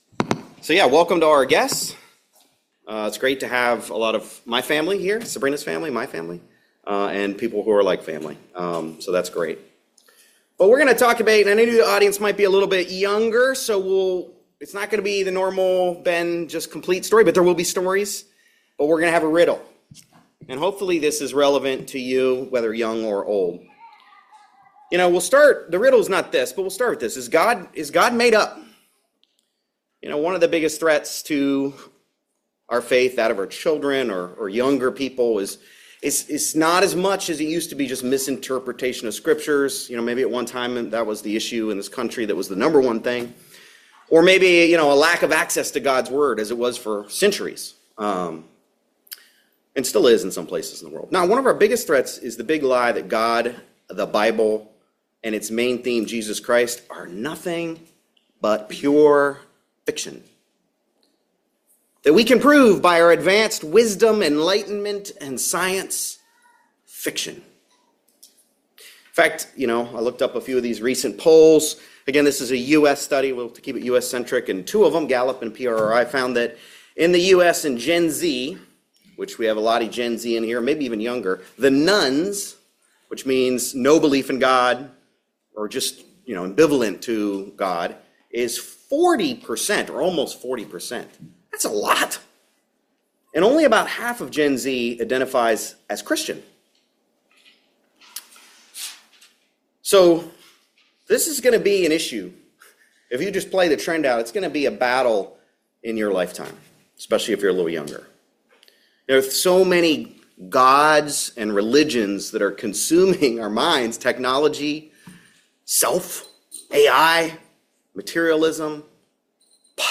Given in Raleigh, NC